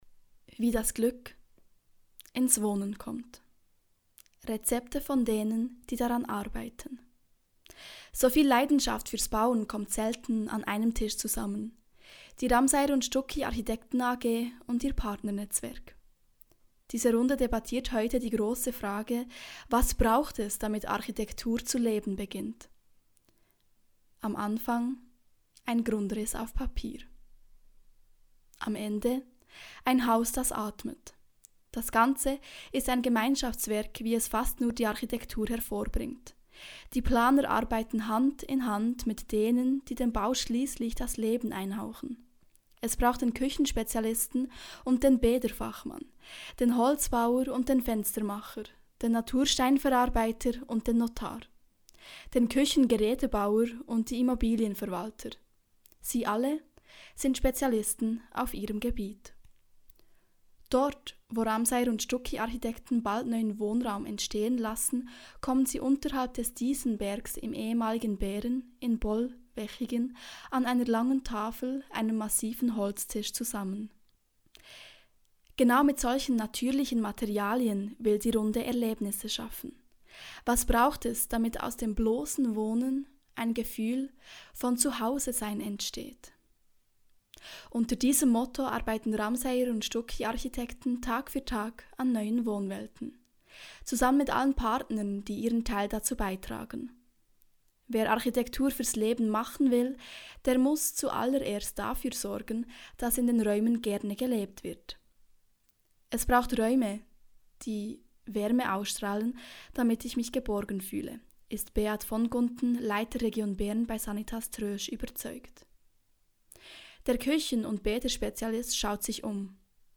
Hoerbuch_VIVRE_01_20.mp3